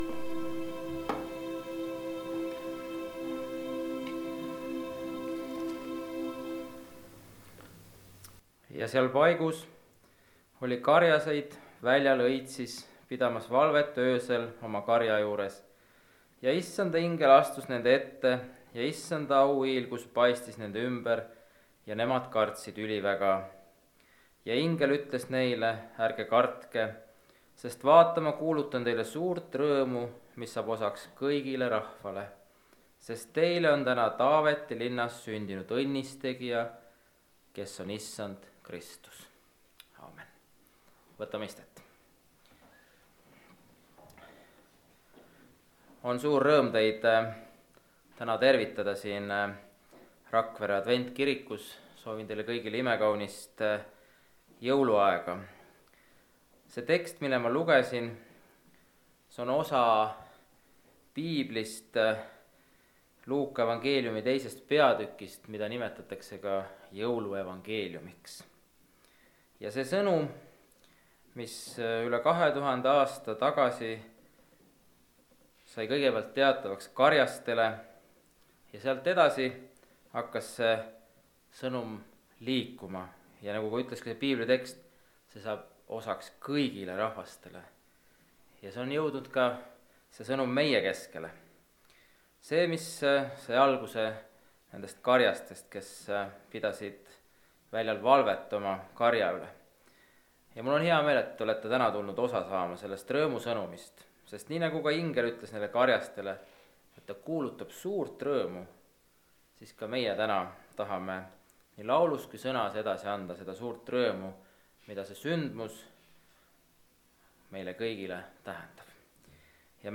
Jõulujumalateenistus (Rakveres)
Koosolekute helisalvestused